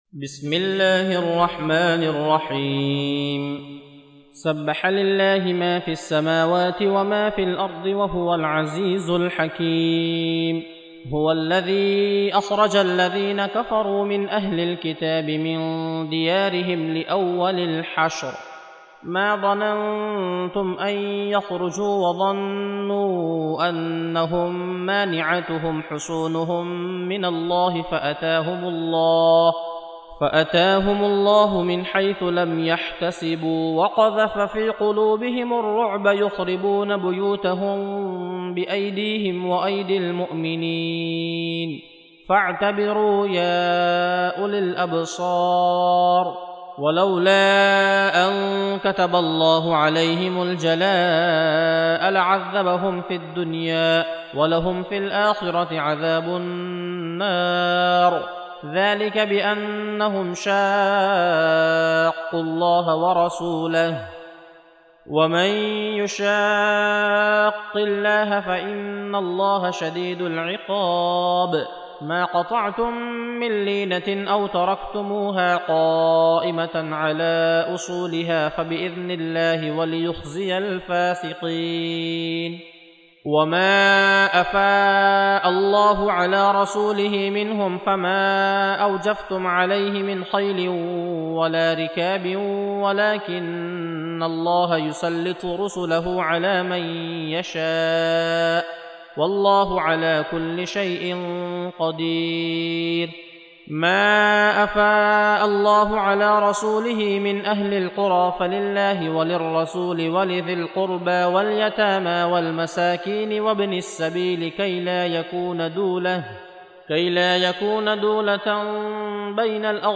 59. Surah Al-Hashr سورة الحشر Audio Quran Tarteel Recitation
حفص عن عاصم Hafs for Assem